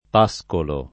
pascolo [ p #S kolo ]